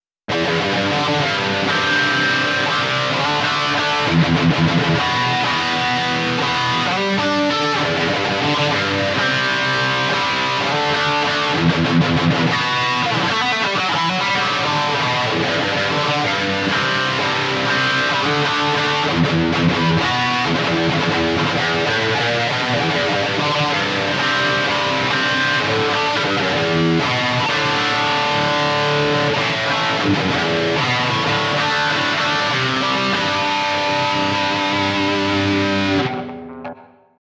This Amp Clone rig pack is made from a SLO II Synergy Module with a matching cab.
RAW AUDIO CLIPS ONLY, NO POST-PROCESSING EFFECTS